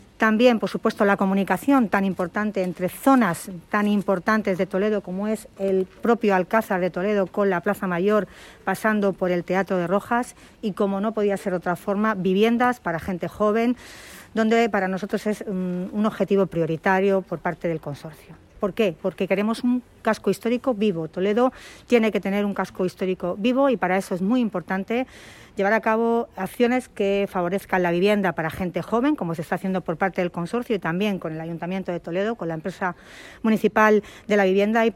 AUDIOS. Milagros Tolón, alcaldesa de Toledo